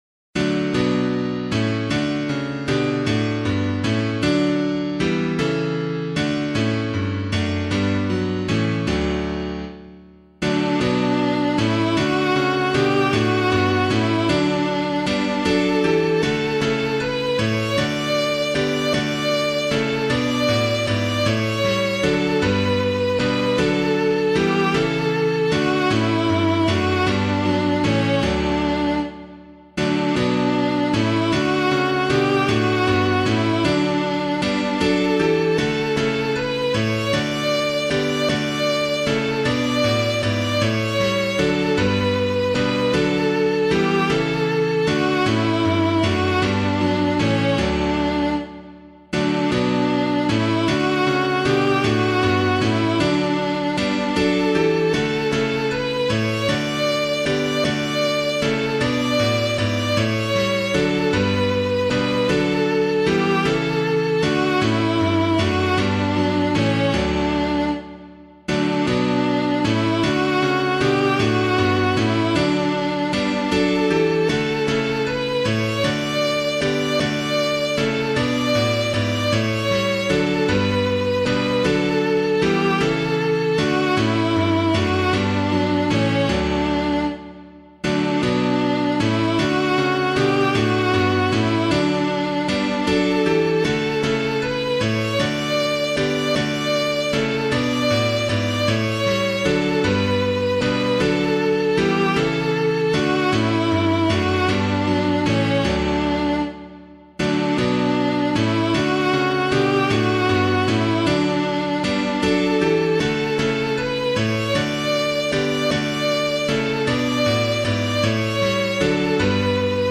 piano
From Lands That See the Sun Arise [Neale - PUER NOBIS] - piano.mp3